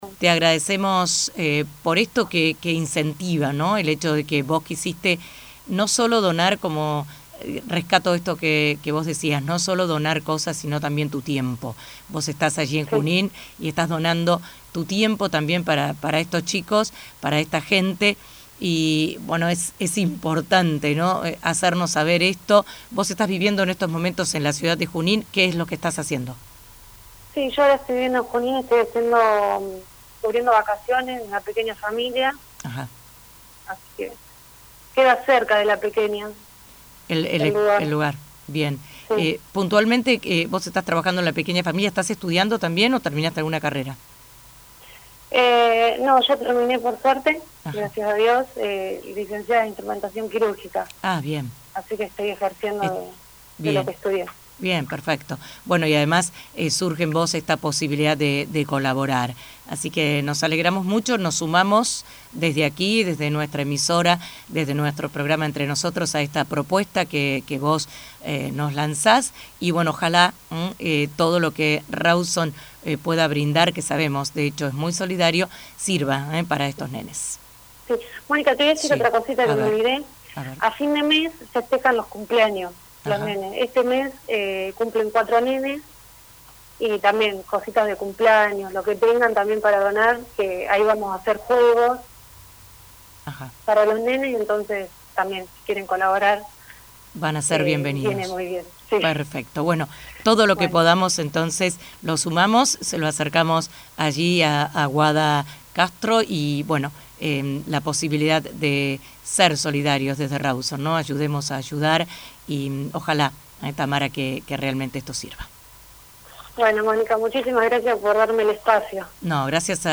Se adjunta audio radial.